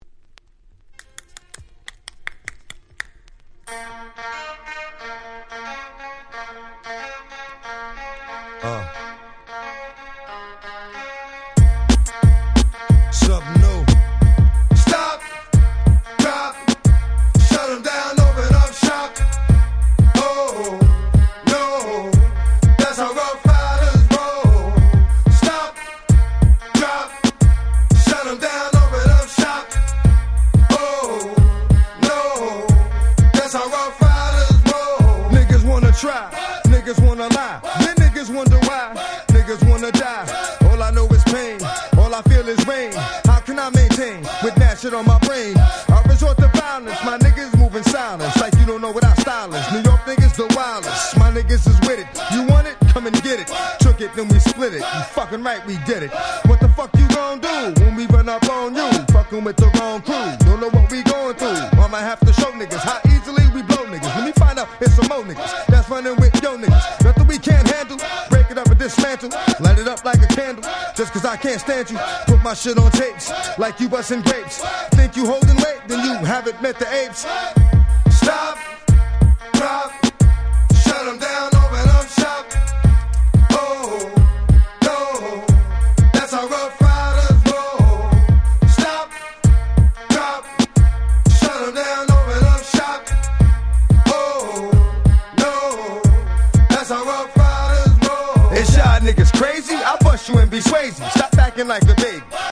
98’ Smash Hit Hip Hop !!